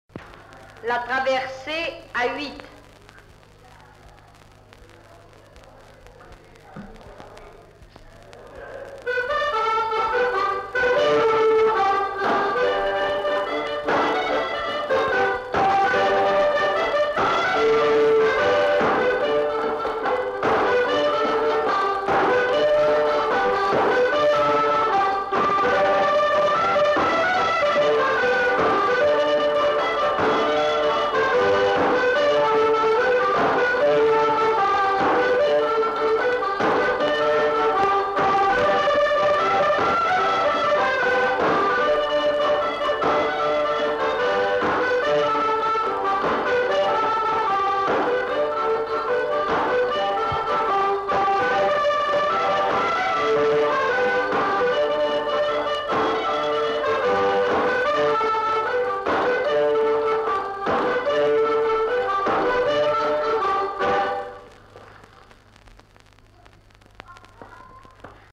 Aire culturelle : Couserans
Genre : morceau instrumental
Instrument de musique : accordéon chromatique
Danse : traversée
Notes consultables : La dame qui annonce les morceaux n'est pas identifiée.